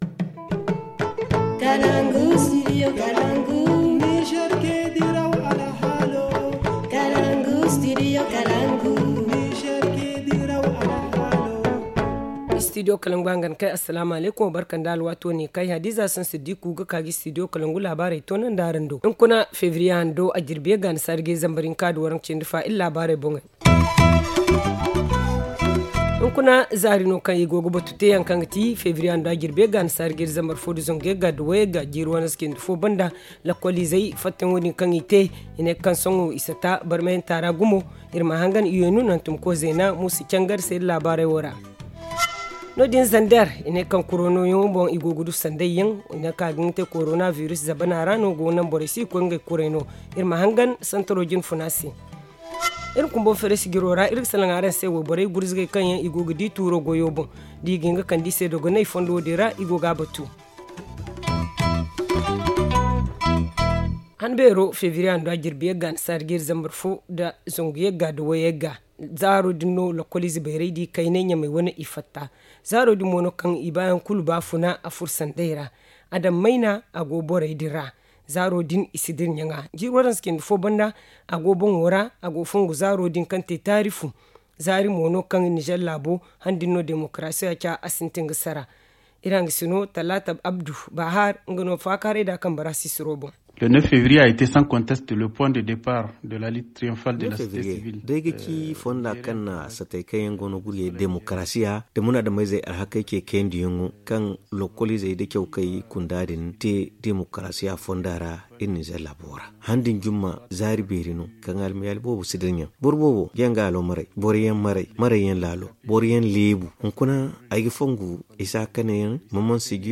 Le journal du 09 février 2021 - Studio Kalangou - Au rythme du Niger